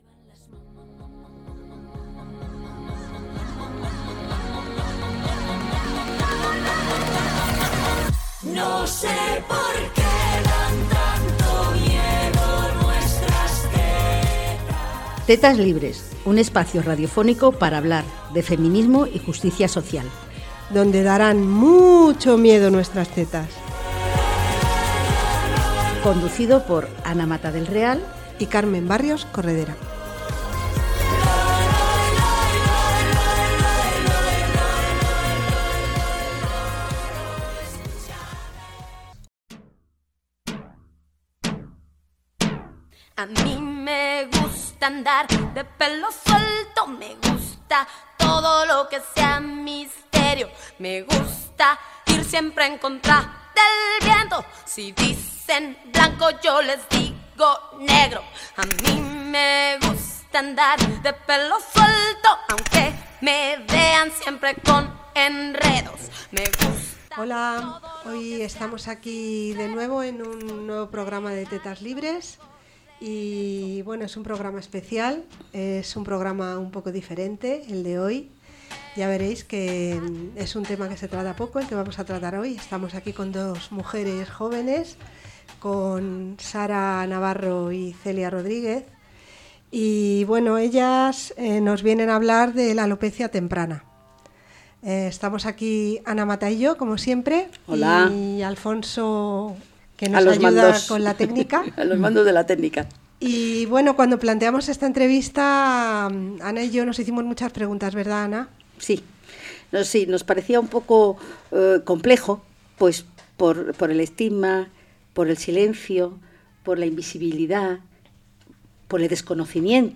dos mujeres con alopecia temprana dedicadas al activismo para visibilizar esta enfermedad, normalizarla y exigir ayudas públicas y apoyo sanitario integral.